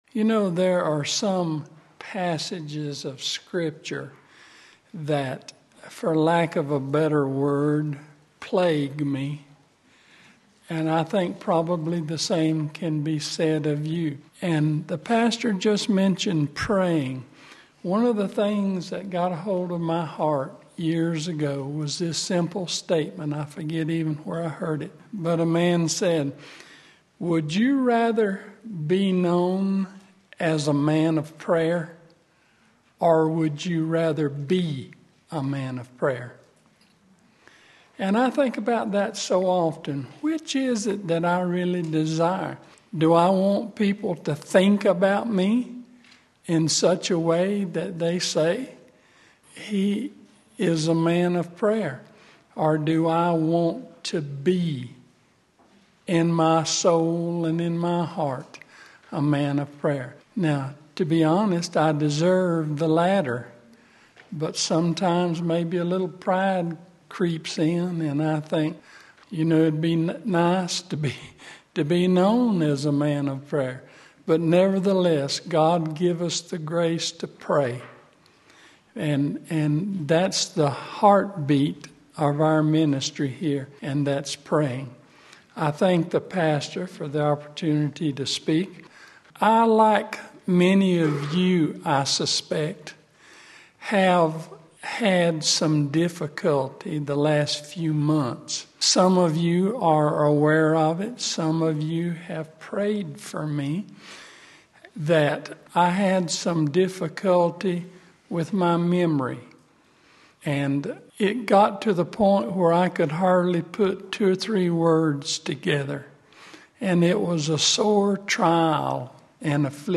Jeremiah's Temple Sermon Jeremiah's Temple Sermon Jeremiah 7:1-12 Sunday Morning Service